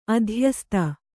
♪ adhyasta